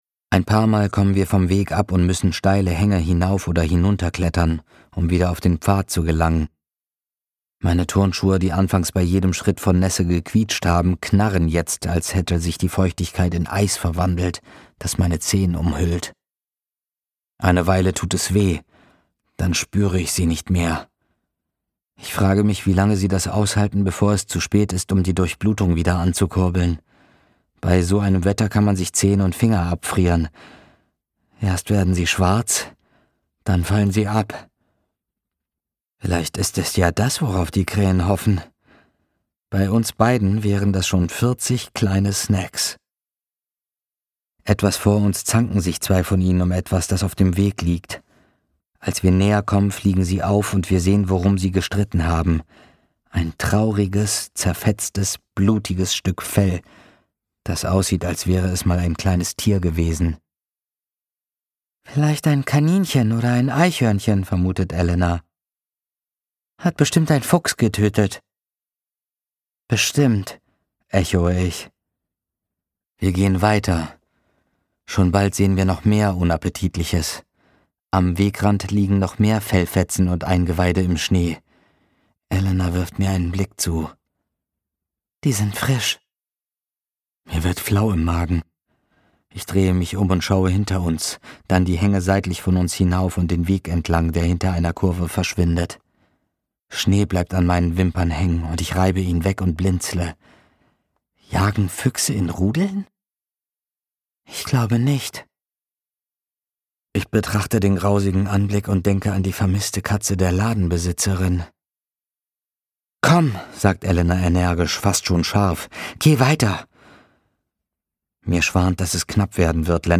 mit jugendlicher Spielfreude die Werwölfe heulen, die Flinten knallen und den Grusel lebendig werden
Gekürzt Autorisierte, d.h. von Autor:innen und / oder Verlagen freigegebene, bearbeitete Fassung.